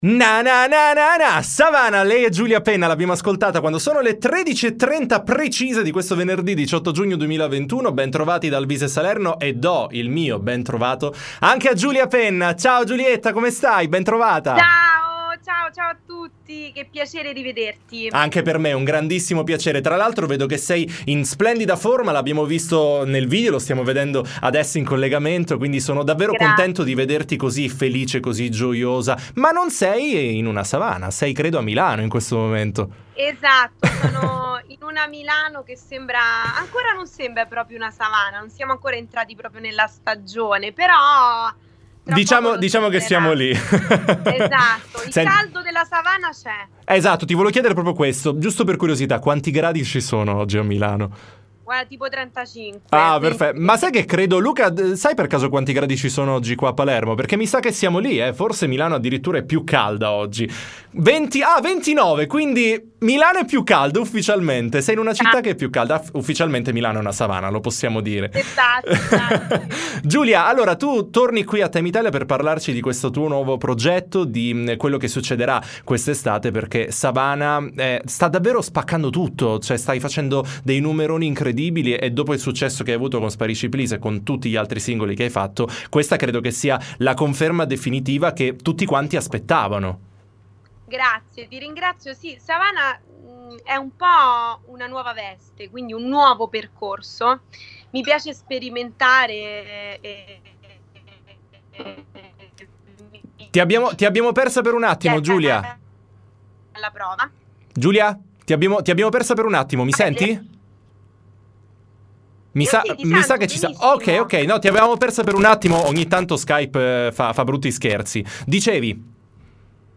T.I. Intervista Giulia Penna